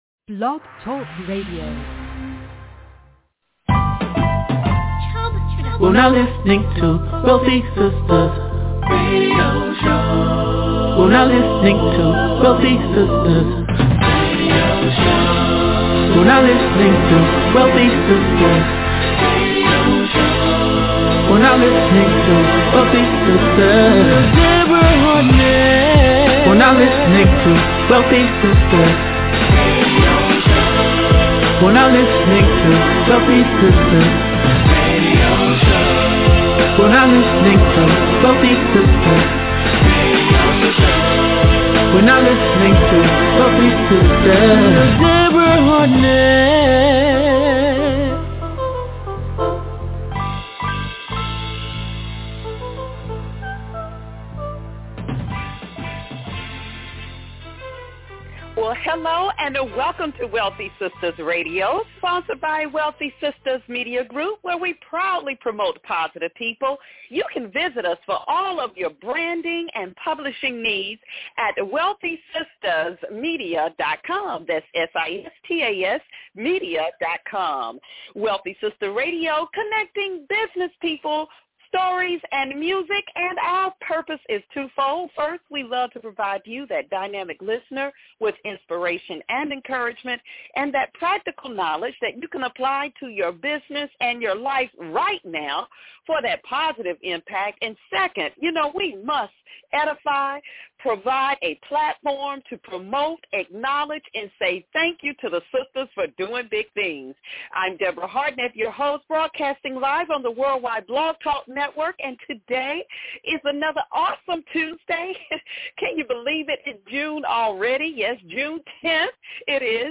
And is intertwined with some of the greatest R&B music of all time.